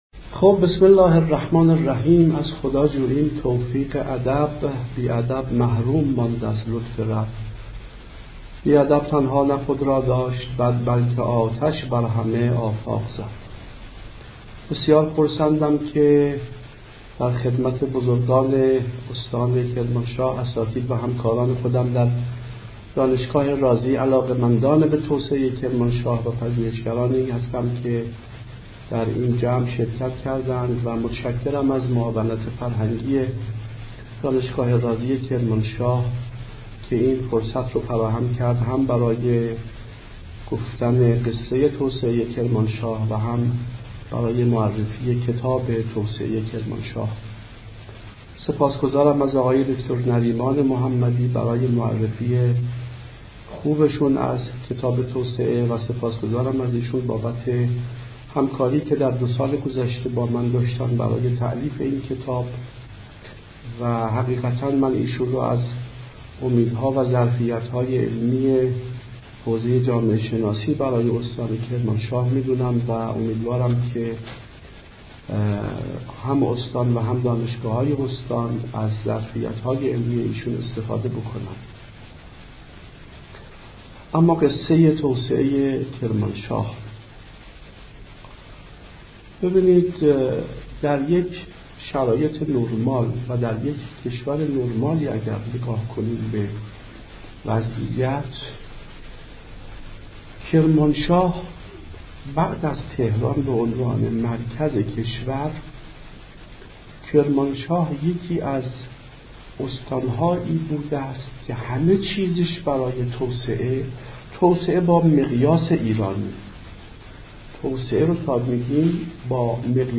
روز دوشنبه ۲۹ شهریورماه سال جاری با سخنرانی دکتر «محسن رنانی»؛ استاد اقتصاد دانشگاه اصفهان به‌صورت مجازی